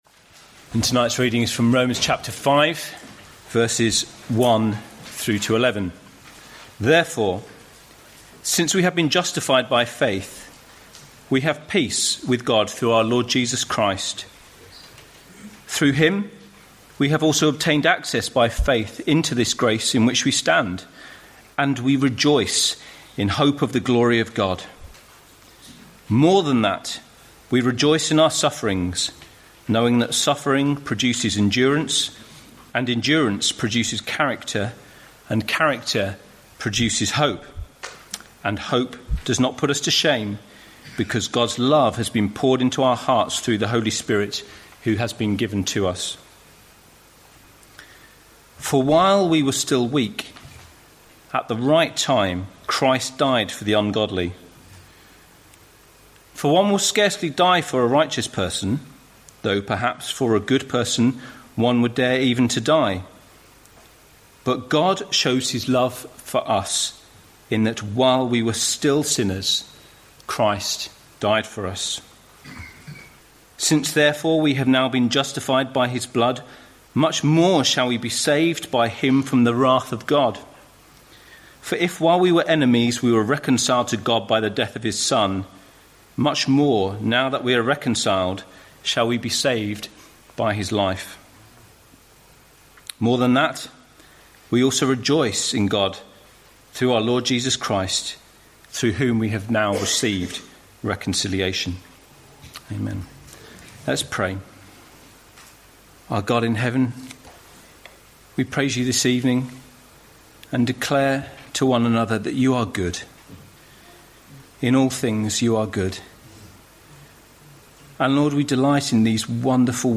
2018 Autumn Lectures